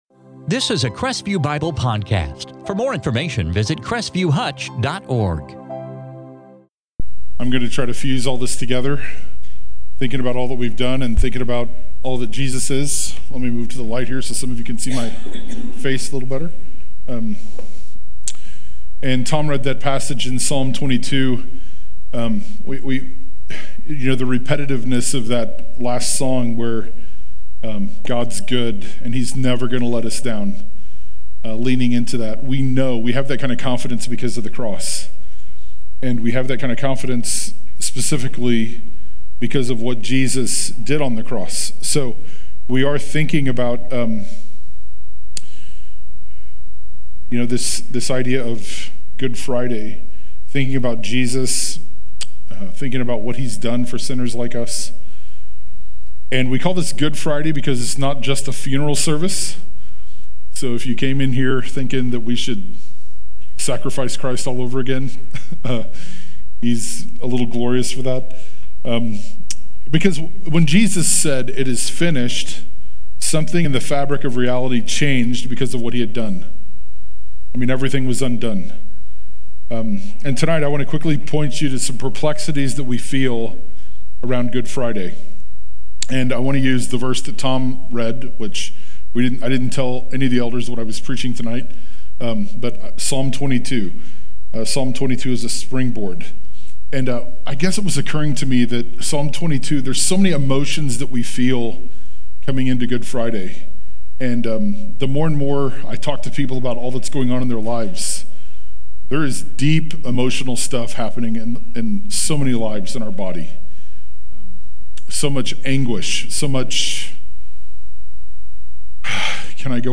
2023 Stand Alone Sermons Psalm Transcript In this Good Friday sermon from Psalm 22